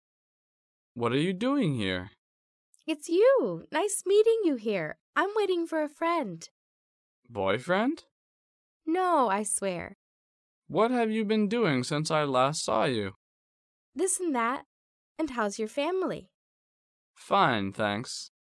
第一，迷你对话